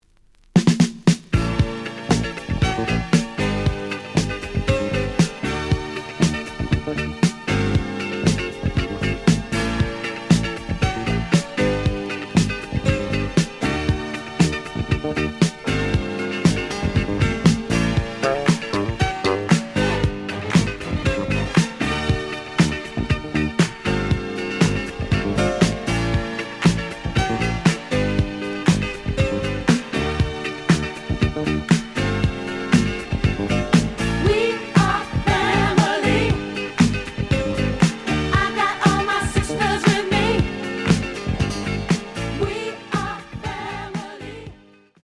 The audio sample is recorded from the actual item.
●Genre: Disco
Slight edge warp.